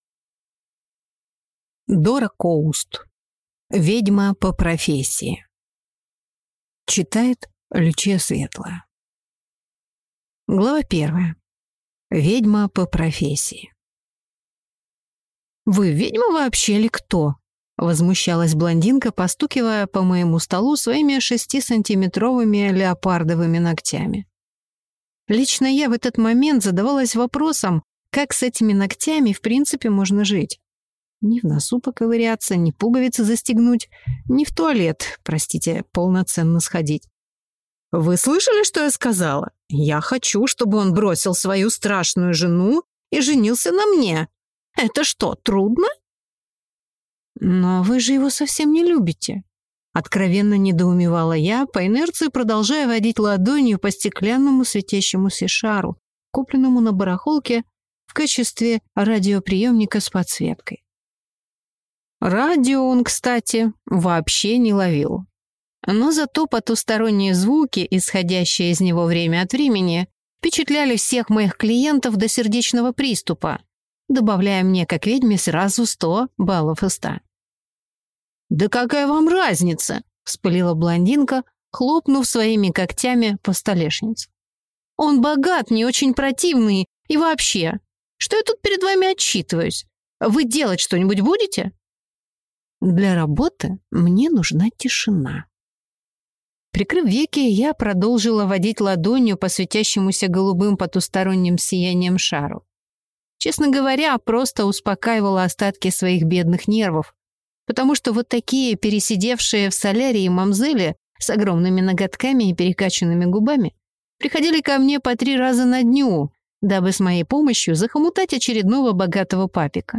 Аудиокнига Ведьма по профессии | Библиотека аудиокниг